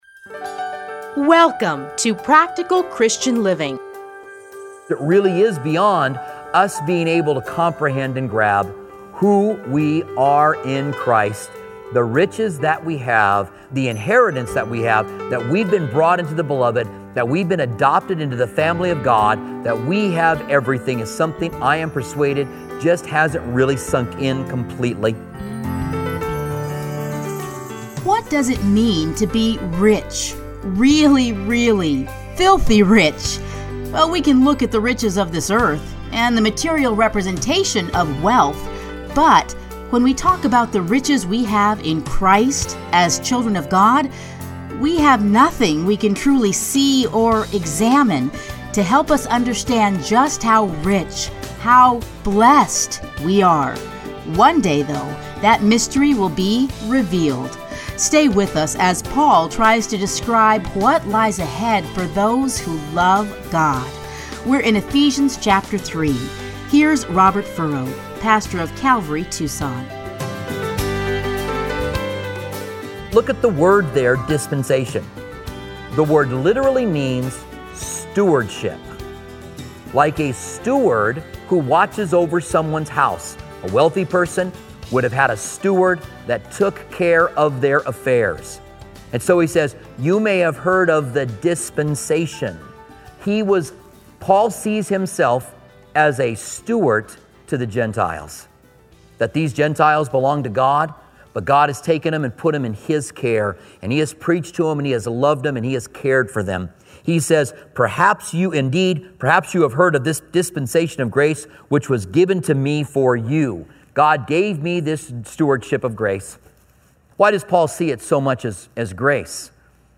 Listen here to his commentary on Ephesians.